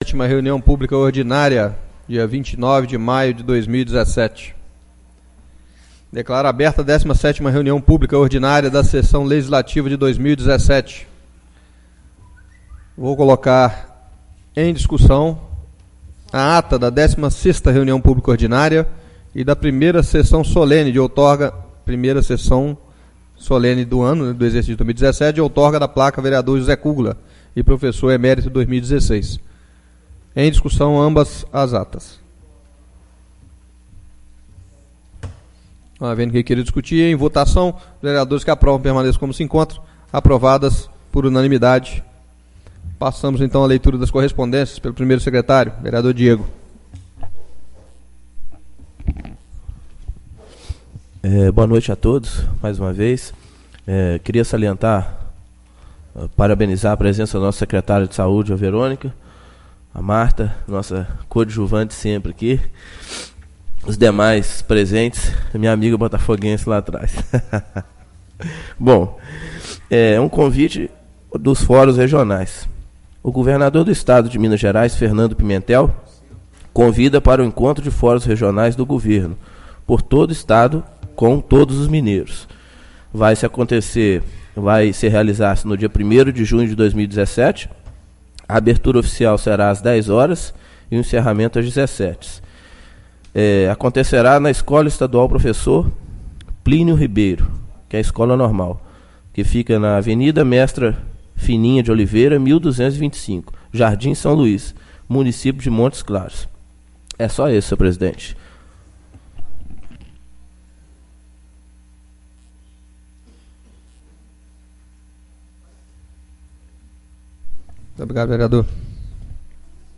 17° Reunião Publica Ordinária 29/05/2017 — Câmara Municipal